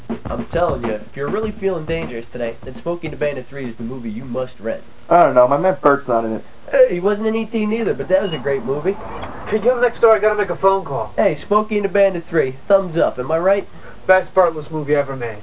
All of these sounds have been digitized